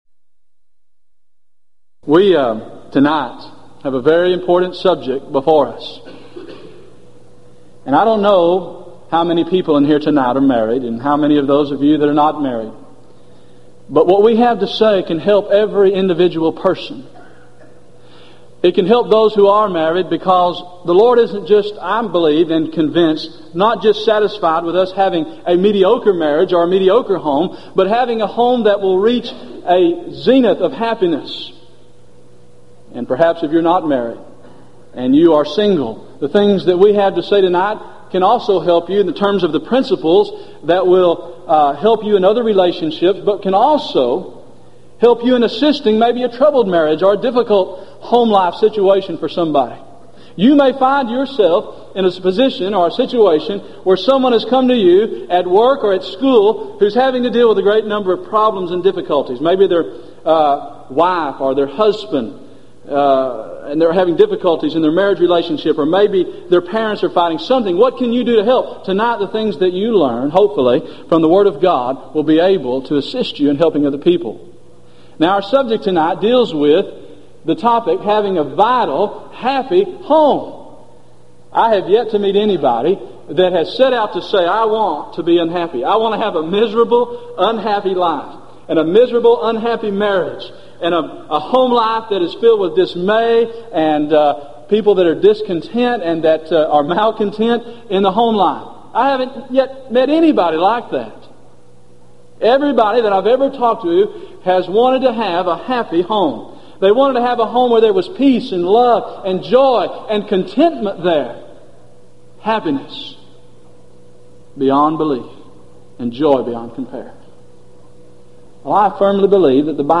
Event: 1997 Gulf Coast Lectures
lecture